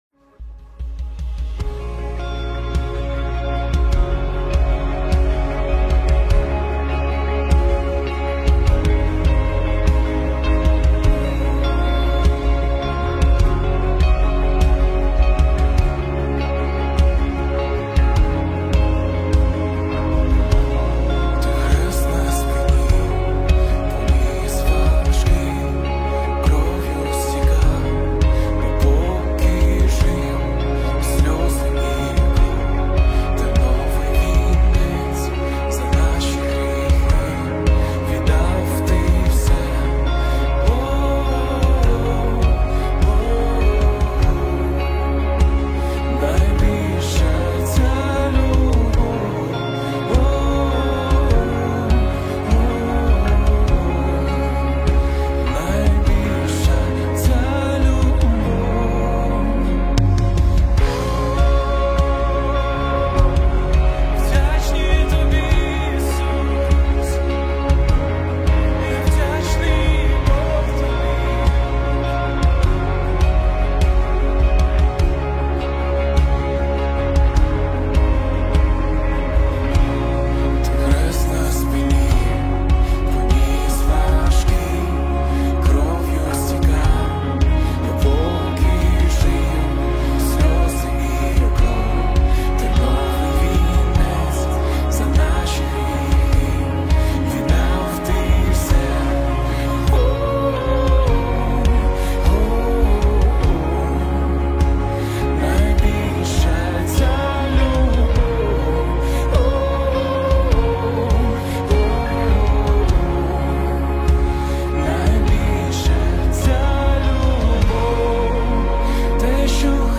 Worship Music
BPM: 76 • Time Sig: 6/8